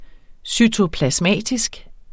Udtale [ sytoplasˈmæˀtisg ]